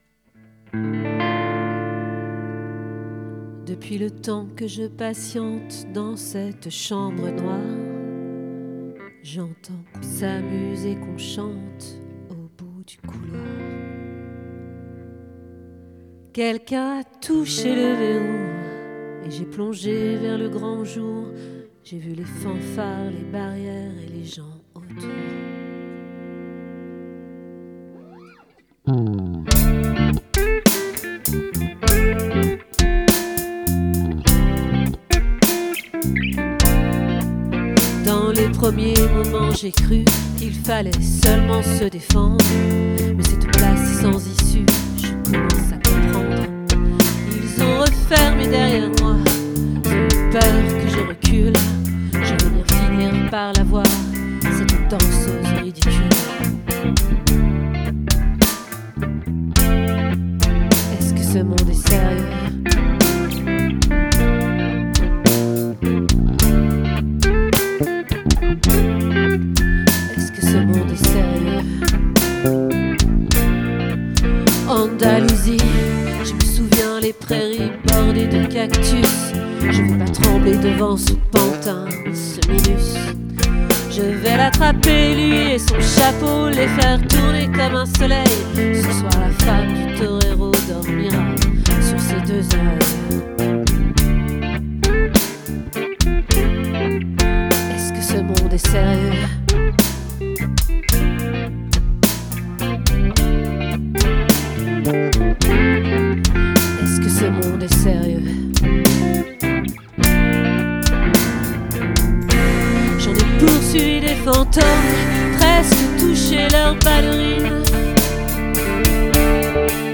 🏠 Accueil Repetitions Records_2024_12_09